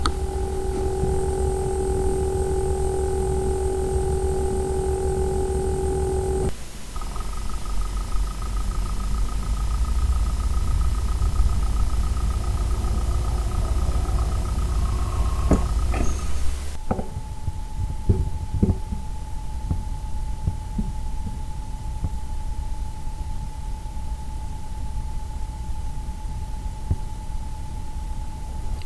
Das Netzteil hört sich an wie ein Dieselmotor im Stillstand. Der Lüfter gibt ein richtig nervtötendes Rattern von sich.
Ich habe eine MP3 angehängt mit einer Aufnahme von den Netzteilen. Das erste ist das Thermaltake, das zweite das Seasonic und zum Schluss das BeQuiet. Wobei das BeQuiet noch das das leiseste von den dreien ist.